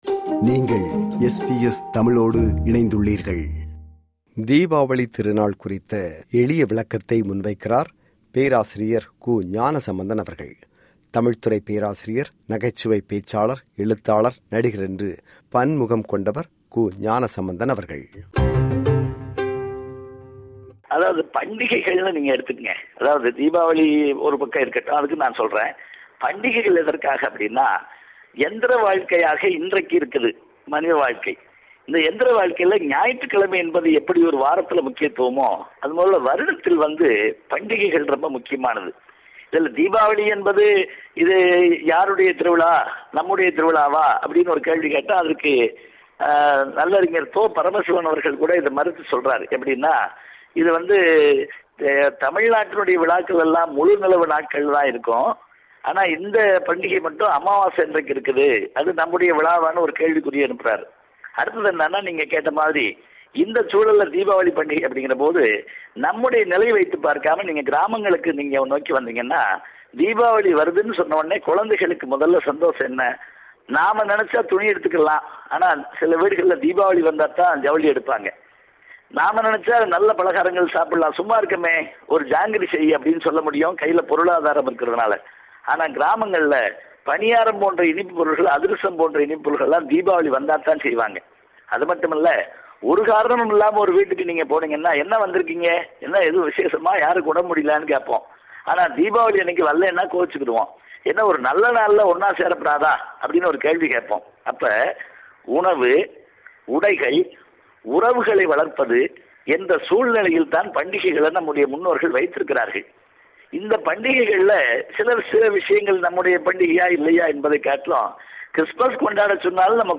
தமிழ்த் துறைப் பேராசிரியர், நகைச்சுவைப் பேச்சாளர், எழுத்தாளர், நடிகர் என்று பன்முகம் கொண்டவர். SBS தமிழ் ஒலிபரப்புக்கு அவர் வழங்கிய நேர்முகத்தில் தீபவாலி குறித்து இப்படி விளக்குகிறார்.